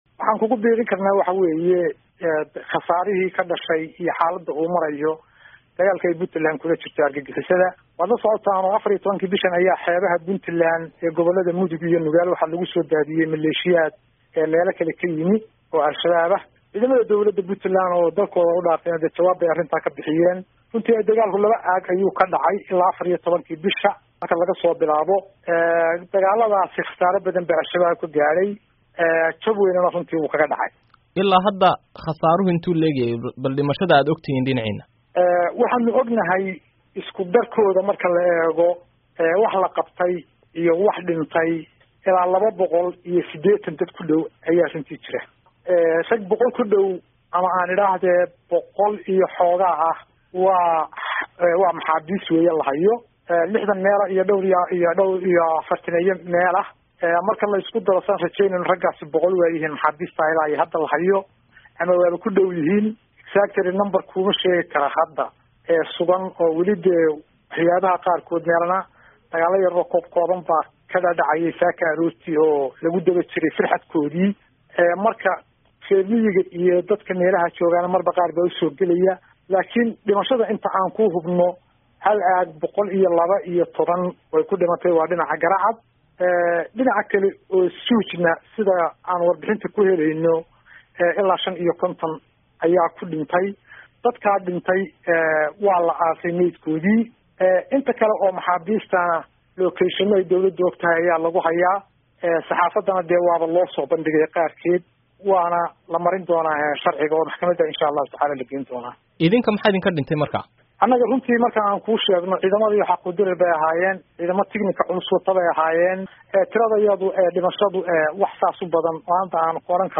Wasiirka Warfaafinta Puntland Maxamuud Xasan Soocadde, ayaa tiradan ku sheegay wareysi uu siiyey VOA.
Wareysi: Wasiirka Warfaafinta Puntland